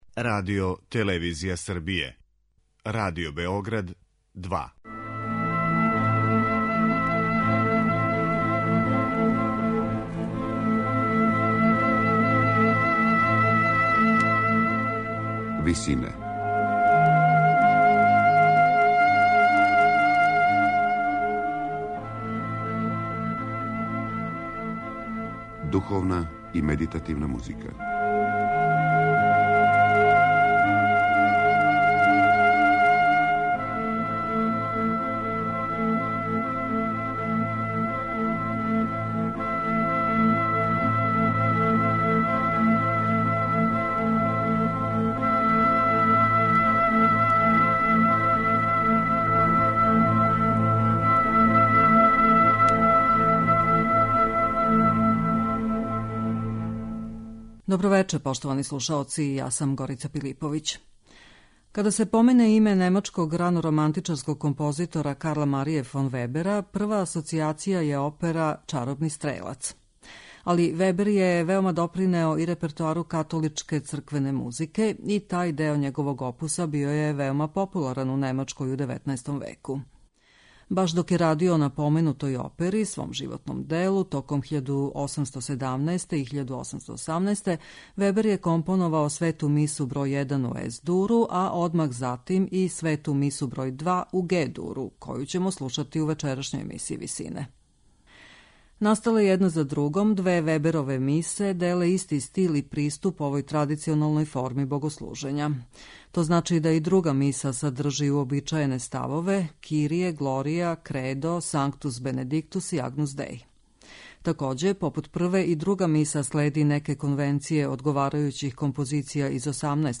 Као што је прва миса добила надимак Миса чаробног стрелца, тако је и друга позната као Jubelmesse односно радосна миса због тога што је то расположење присутно од самог почетка. Управо се по њему друга миса разликује од прве, нарочито у хорским одломцима.